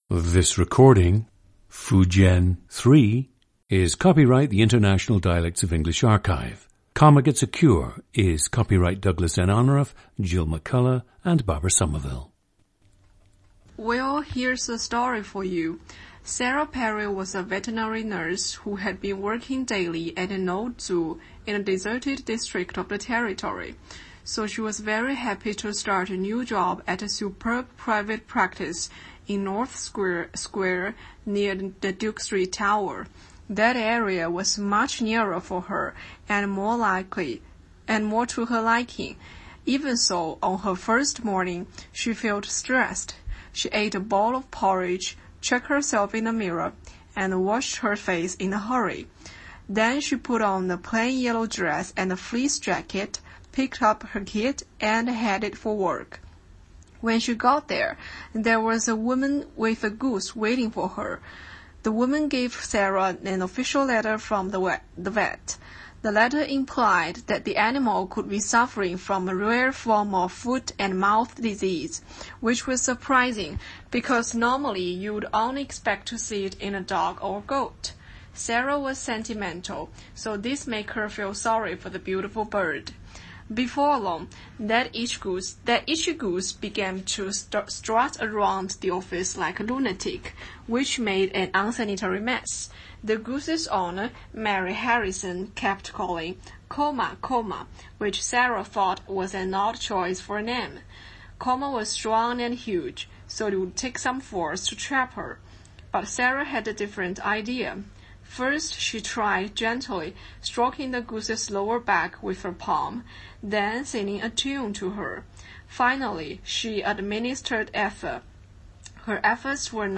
GENDER: female
And at that time, she listened only to tapes recorded in a British accent. She also admits that her reading of Comma Gets a Cure might not be in her true accent, as she’s studied IDEA and listened to multiple recordings of other subjects reciting Comma.
• Recordings of accent/dialect speakers from the region you select.
The recordings average four minutes in length and feature both the reading of one of two standard passages, and some unscripted speech.